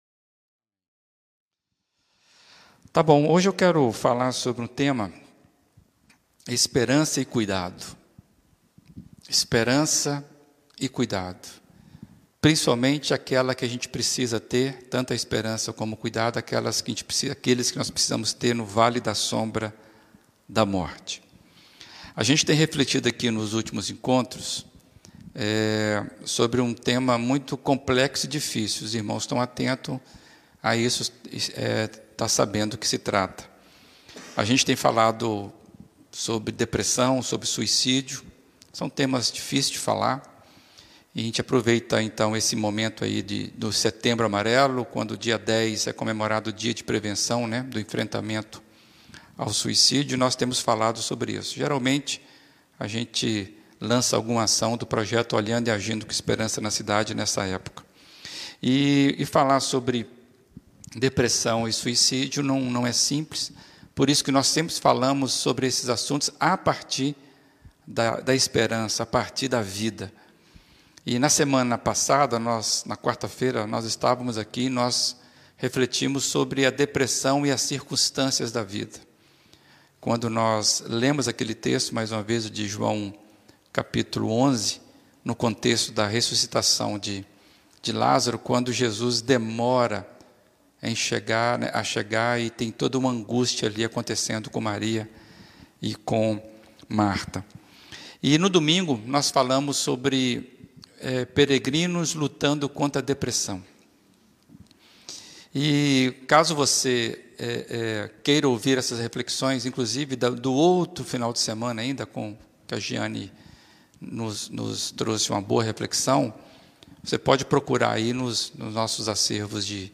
Reflexões das Quartas-feiras